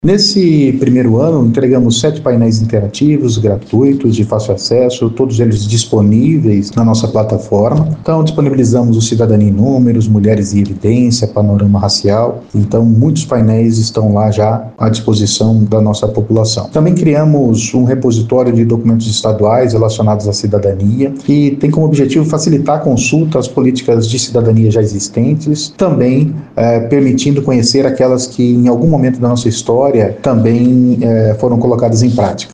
Em entrevista ao programa “Agora 104” o coordenador do projeto